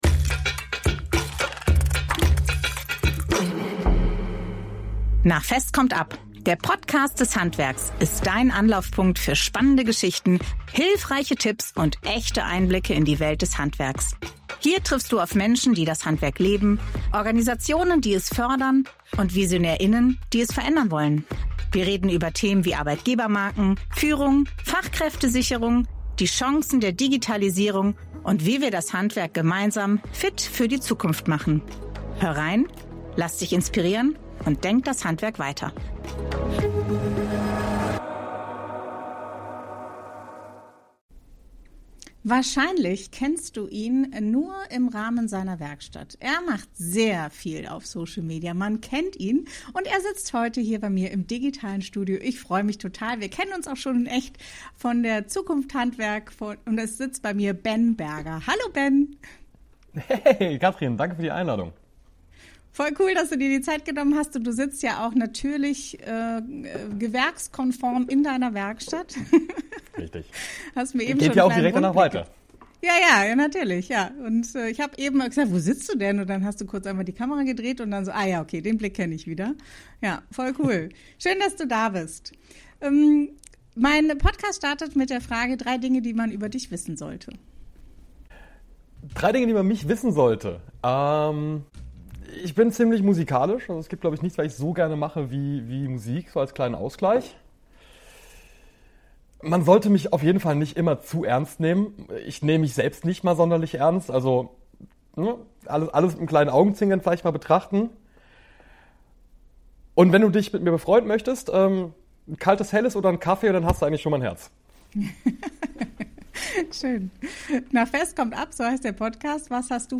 Ein Gespräch darüber, was sich verändern muss – und was wir gemeinsam möglich machen können.